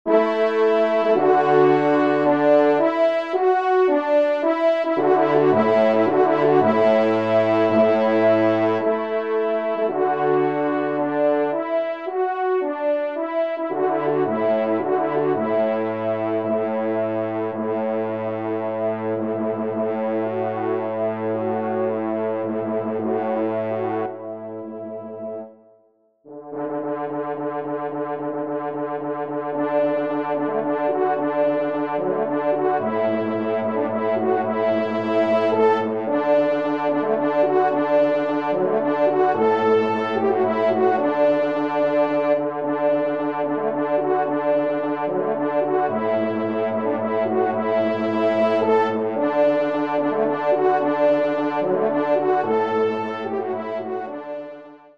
4ème Trompe